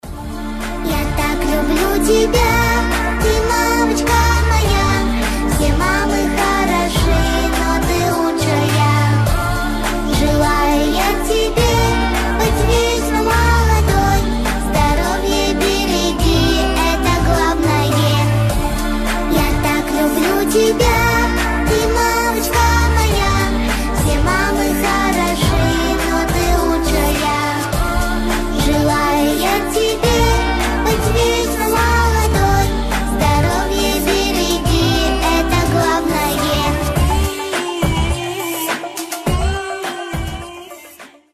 Детский голос
Фолк , Cover